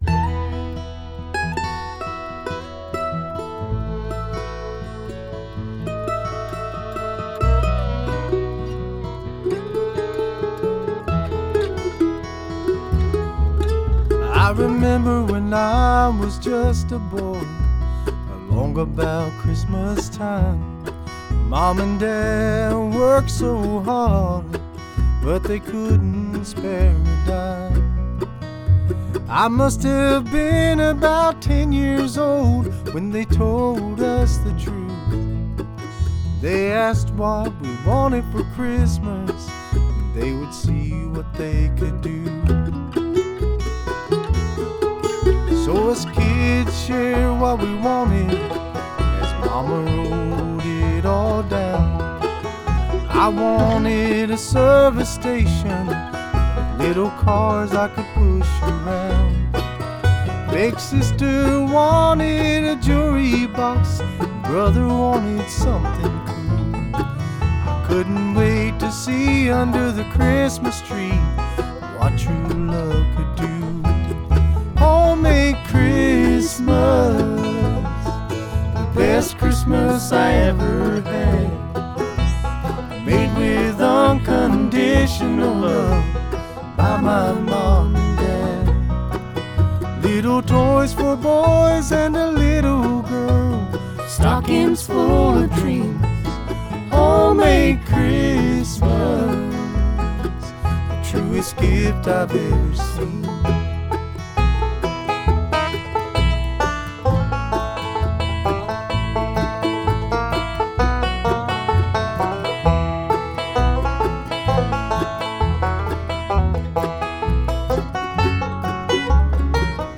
Mandolin
Banjo
Guitar, Vocals
Fiddle, Vocals
Bass